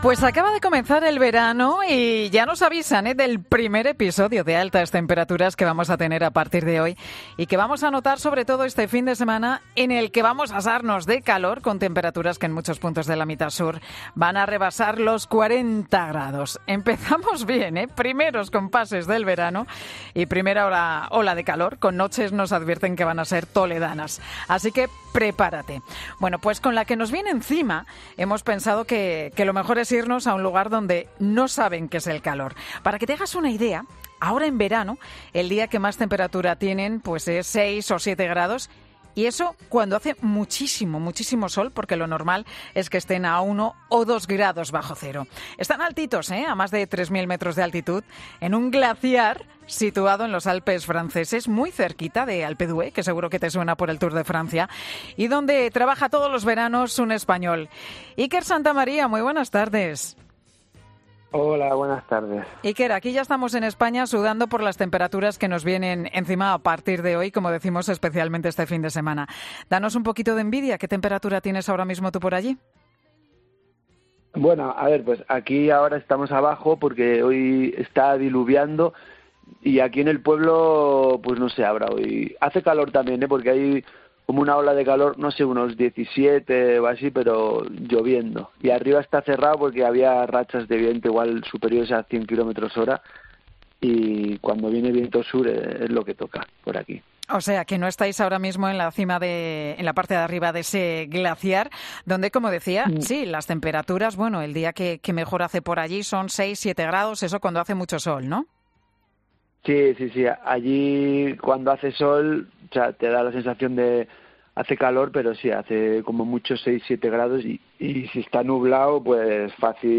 Al terminar el día, indica que acaba destrozado porque las tiene que reparar. Tienes aquí la entrevista completa.